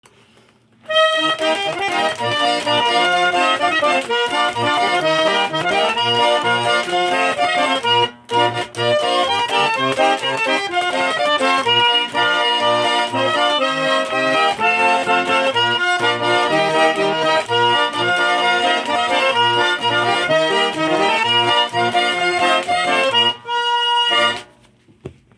Гармонь до мажор
Аудиозапись звучания